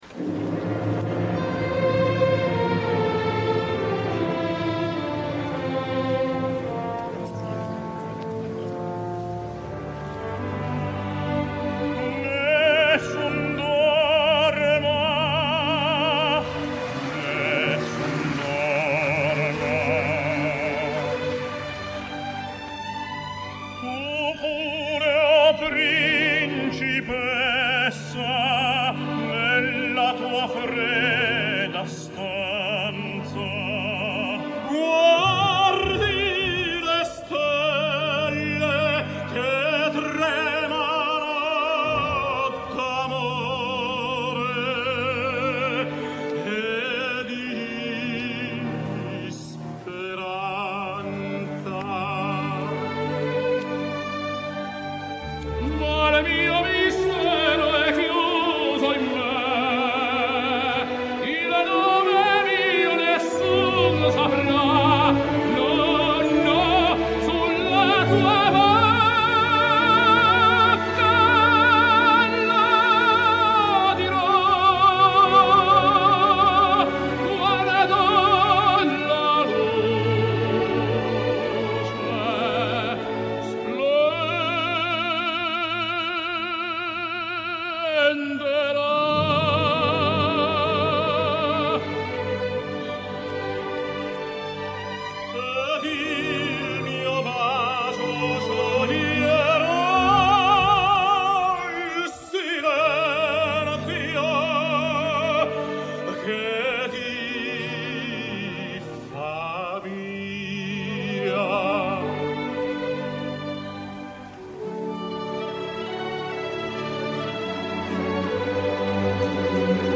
Tenors singing Nessun dorma
Pavarotti/Domingo/Carreras